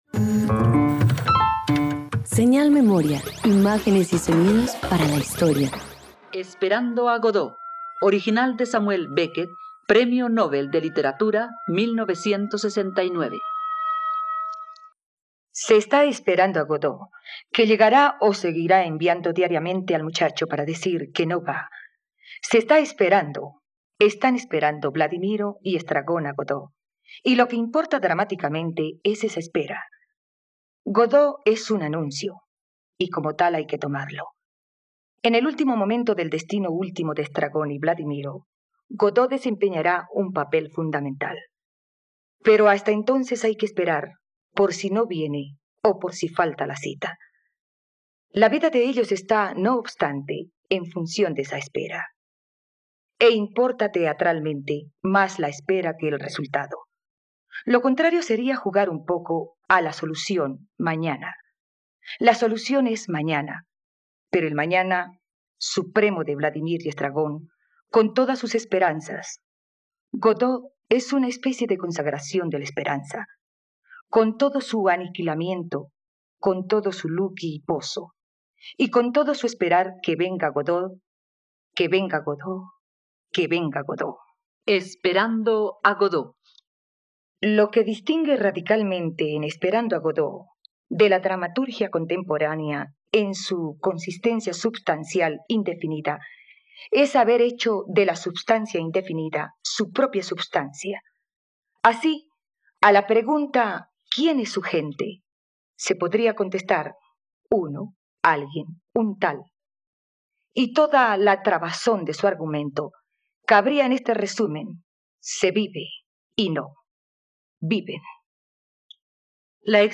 Esperando a Godot - Radioteatro dominical | RTVCPlay
..Conoce la versión para radioteatro del original "Esperando a Godot" del dramaturgo Samuel Beckett.